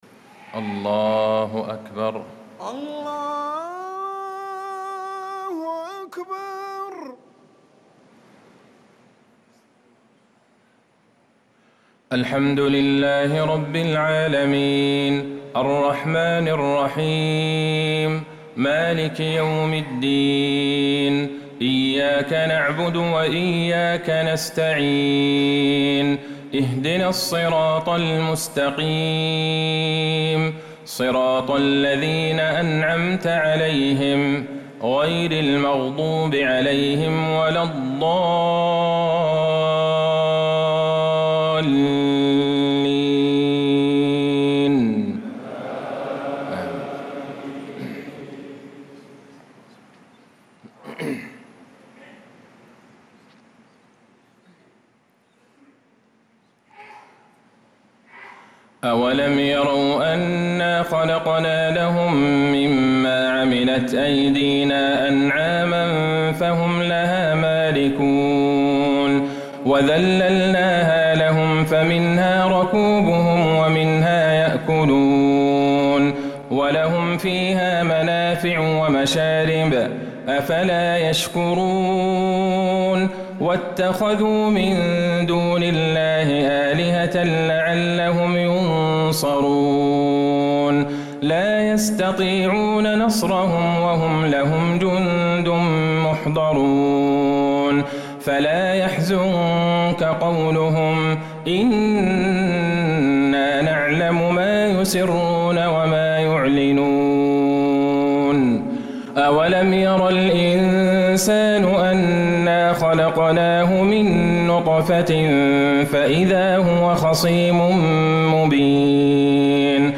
صلاة العشاء للقارئ ماهر المعيقلي 24 صفر 1446 هـ